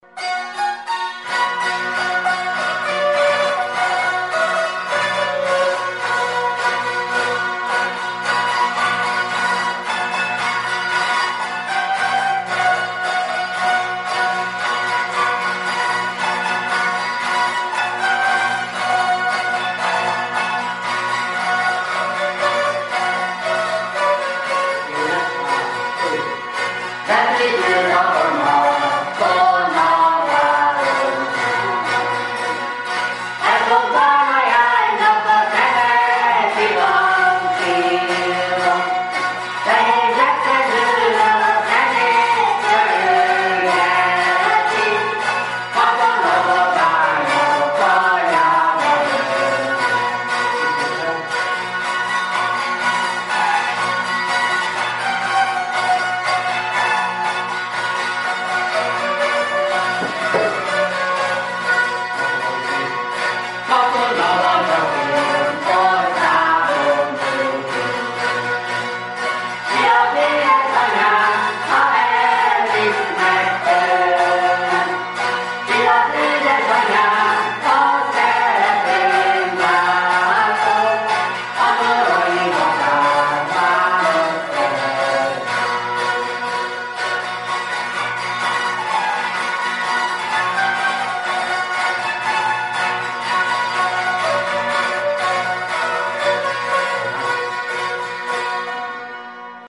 Iskola: звуки ліри та пісня